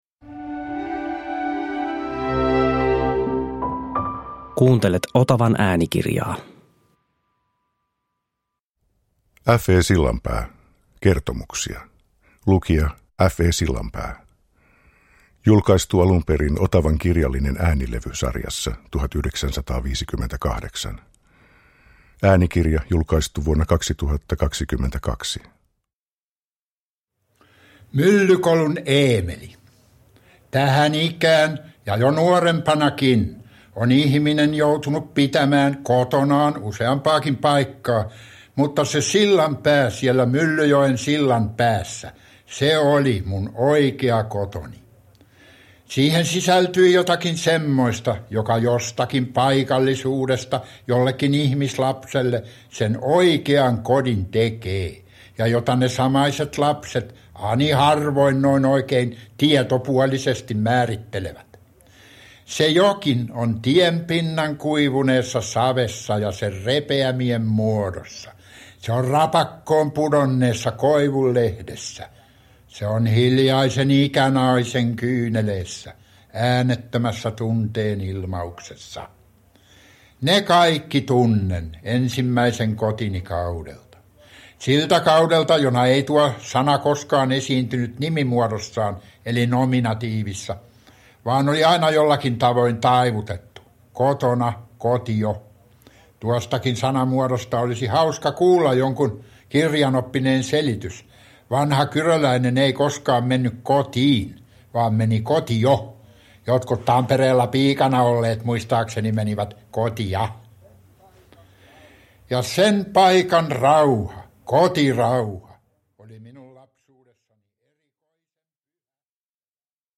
Kertomuksia – Ljudbok – Laddas ner
Kirjallisuuden nobelisti F. E. Sillanpää kertoo tällä äänitteellä lapsuudestaan ja nuoruudestaan. Radiossa tutuksi tullut koko kansan Taata tarinoi leppoisalla, rohkeasti tunnelmoivalla tyylillä lapsuudestaan Myllykolun Eemelinä Hämeenkyrön Kierikkalan kylässä.
Äänitys on tehty 1950-luvulla jolloin Sillanpää (1888-1964) julkaisi useita muistelmateoksia lapsuudestaan ja nuoruudestaan.